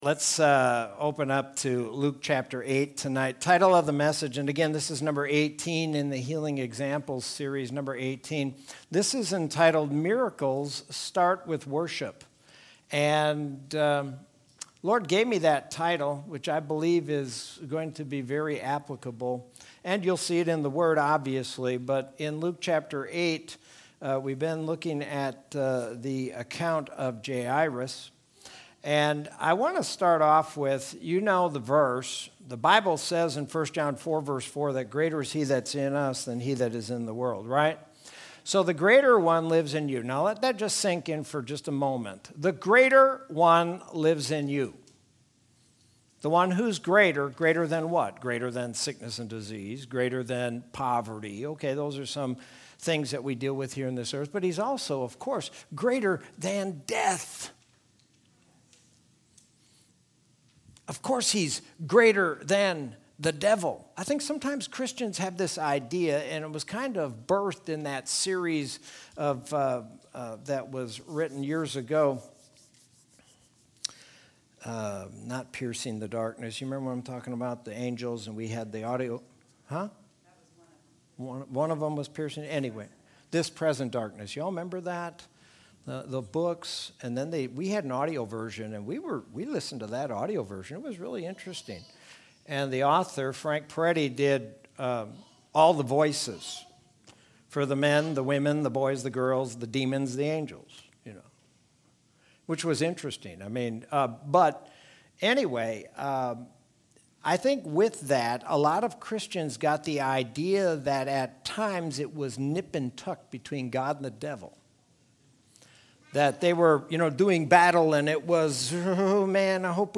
Sermon from Wednesday, June 9th, 2021.